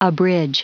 Prononciation du mot abridge en anglais (fichier audio)